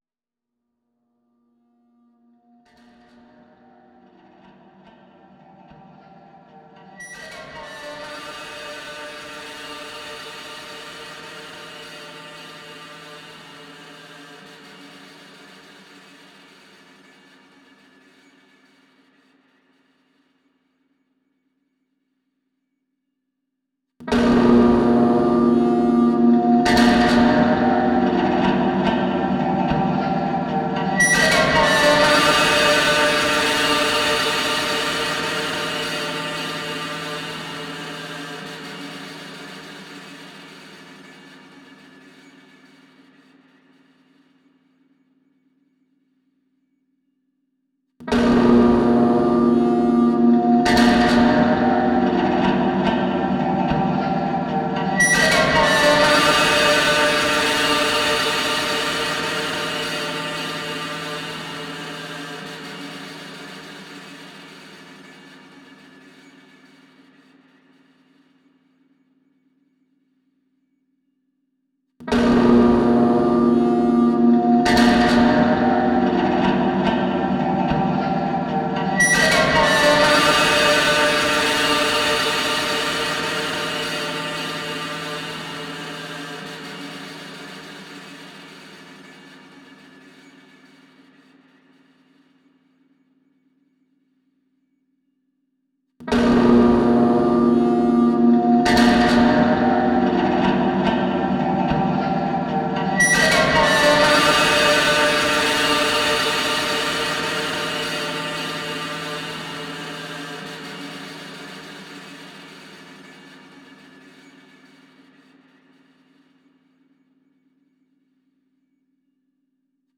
ギター愛好家の方々にはもちろん、現代音楽、先端的テクノ、実験音楽をお好きな方々にもお薦めのアルバムです。